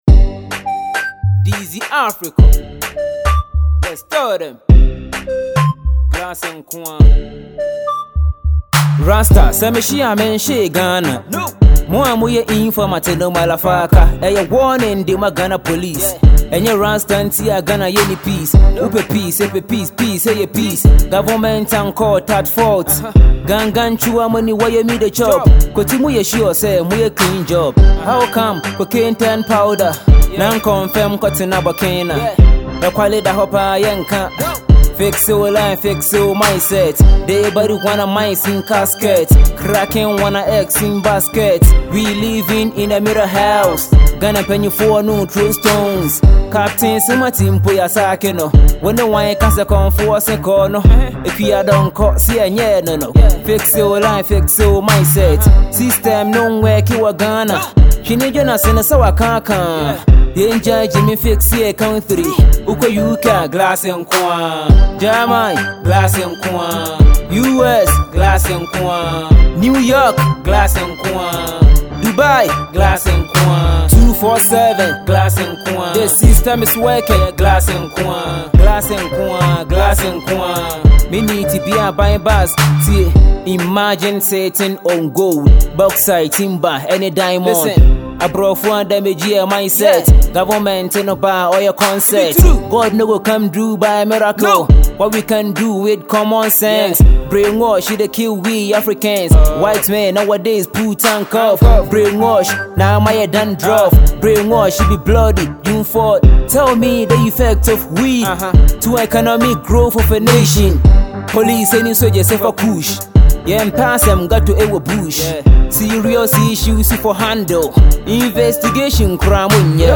Ghana Music Music
Ghanaian rapper